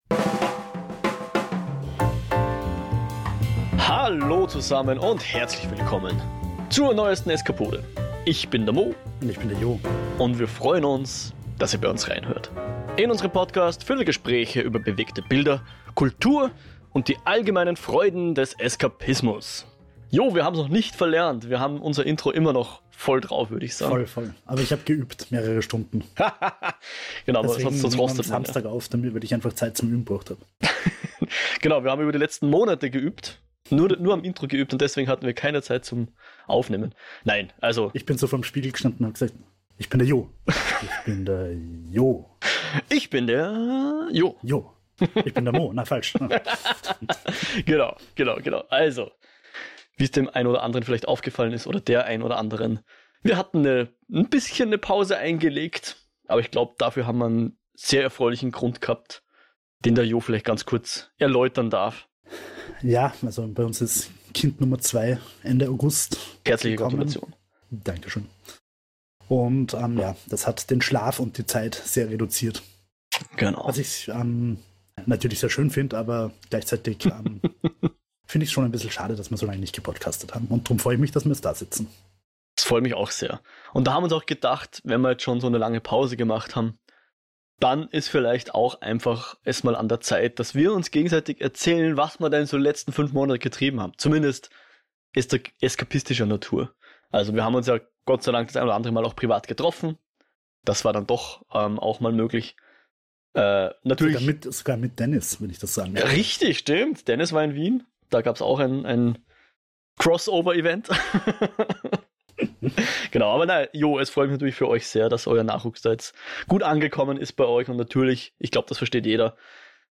Gespräche über bewegte Bilder, Kultur und die allgemeinen Freuden des Eskapismus